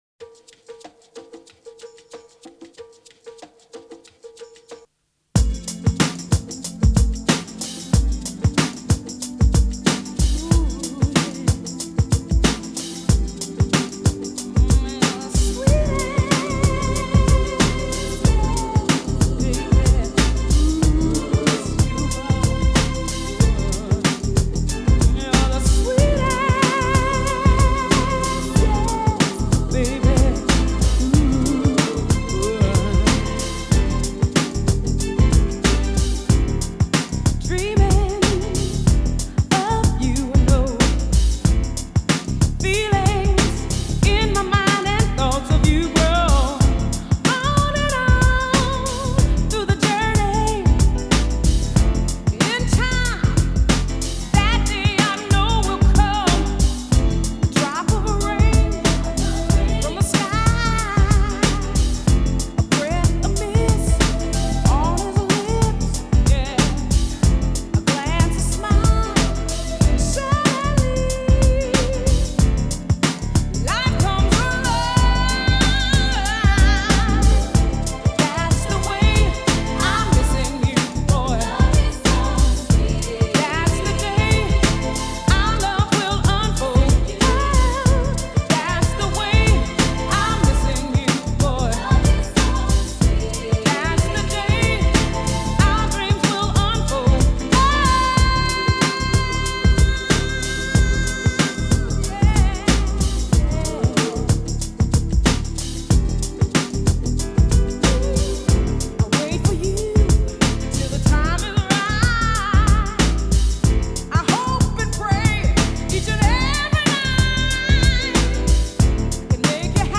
Longing, memory, love, loss